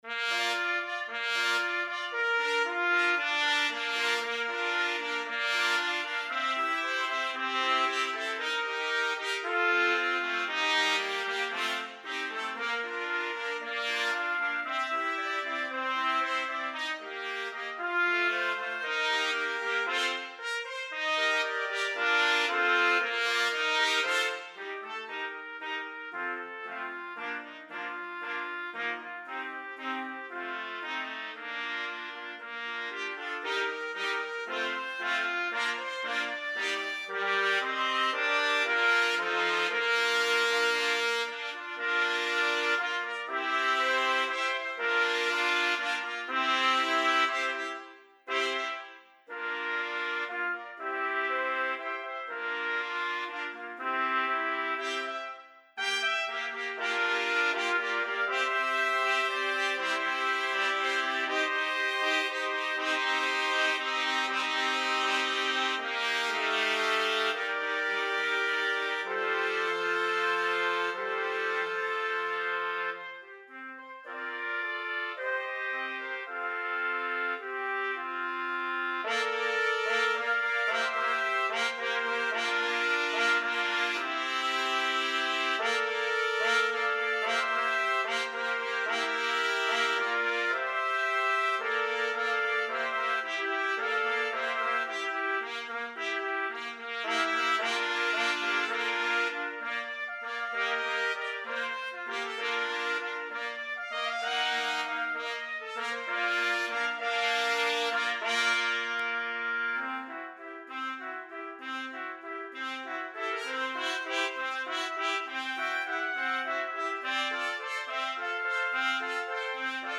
Trumpets Ensemble
Instruments: Trumpets.
digital instruments.